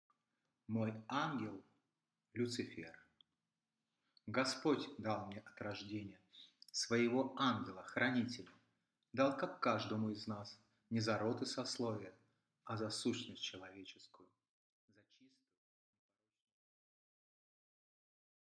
Аудиокнига Ангелы и бесы | Библиотека аудиокниг
Aудиокнига Ангелы и бесы Автор Андрей Воронин Читает аудиокнигу Андрей Воронин.